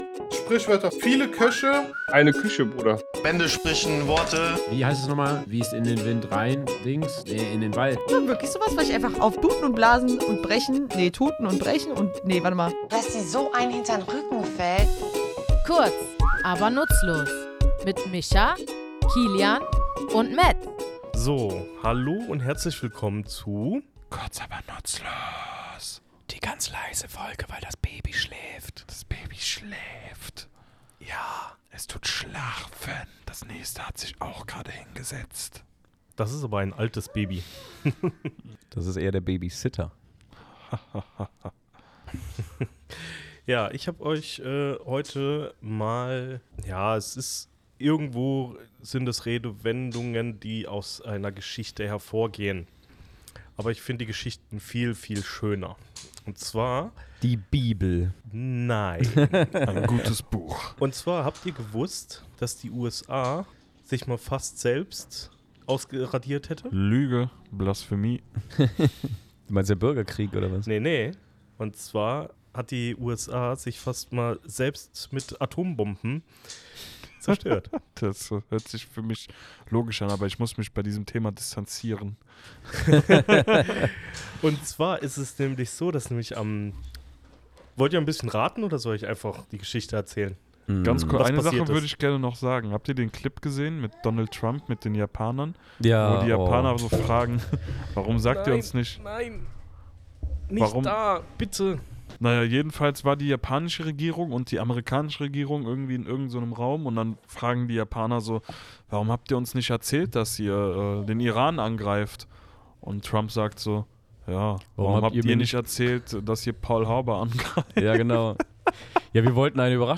Wir, drei tätowierende Sprachliebhaber, quatschen in unserem Tattoostudio über diesen krassen Vorfall und die Sprache dahinter.